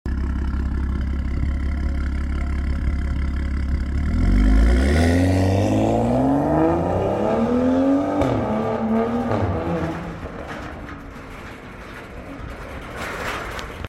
This immaculate BMW f30 335i is equipped with a Rogue Performance Resonated Turbo-Back Exhaust, bringing out those sexy deep N55 exhaust notes 💥